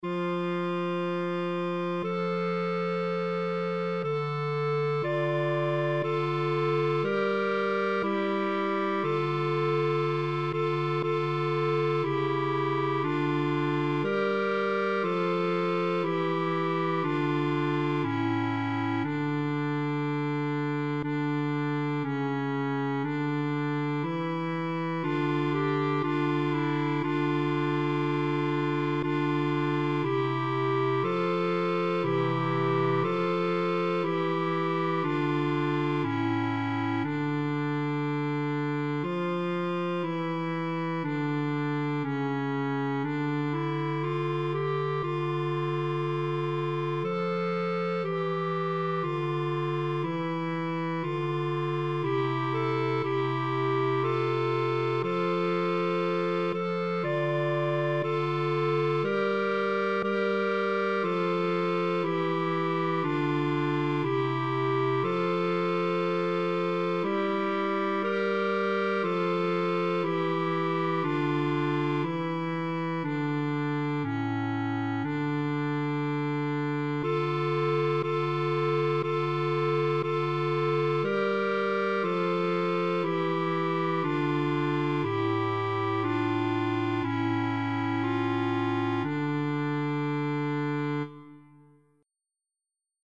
cor mixt